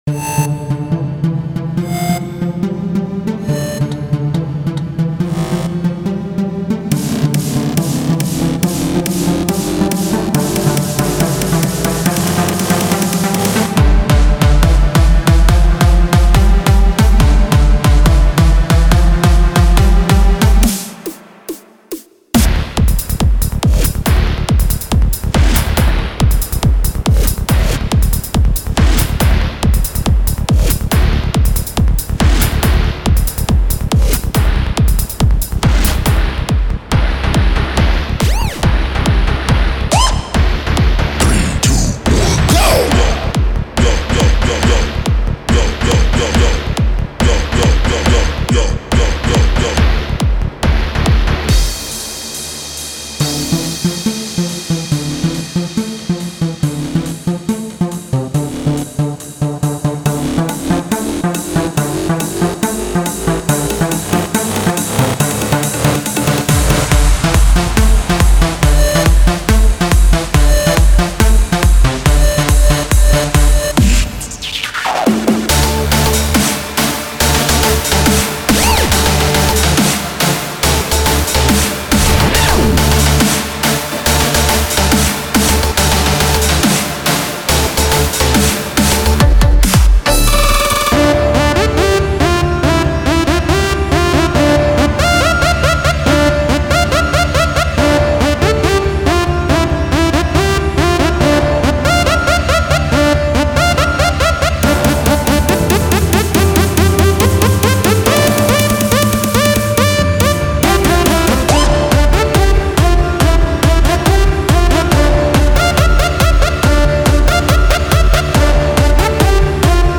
מקצבים לקורג
אחלה סאונדים, אבל למה בלי האקורדים?